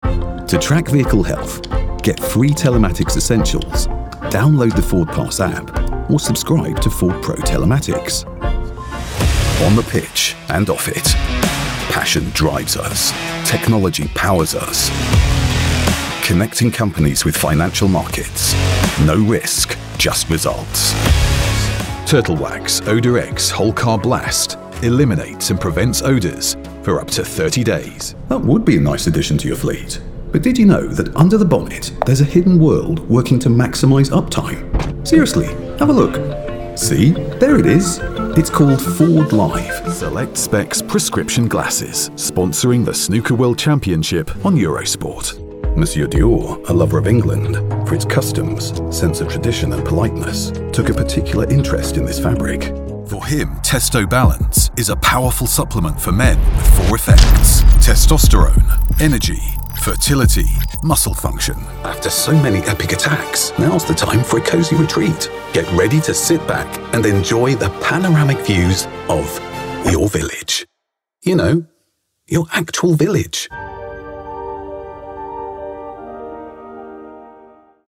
British Radio & TV Commercial Voice Overs Artists
Adult (30-50) | Older Sound (50+)